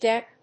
ディーイーエッチ‐ピー